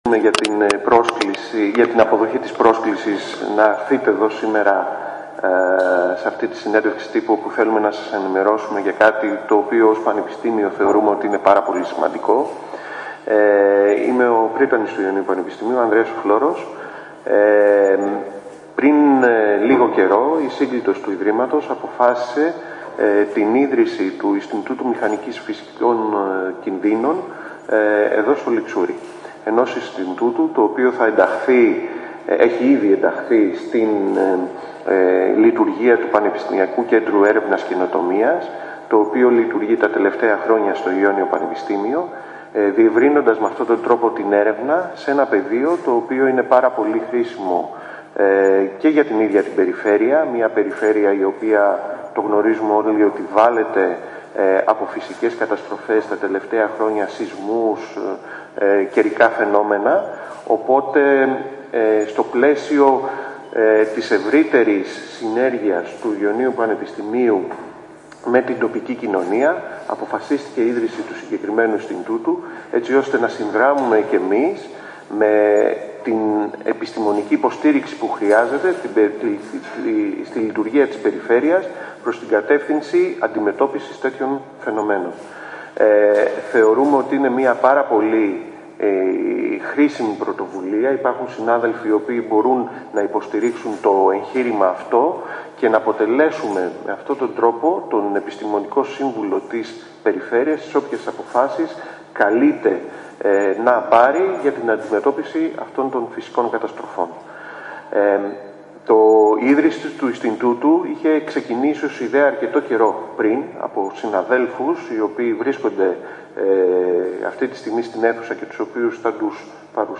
Στην αίθουσα του Τμήματος Εθνομουσικολογίας στο Ληξούρι βρεθήκαμε σήμερα το πρωί